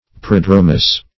Prodromous \Prod"ro*mous\, a.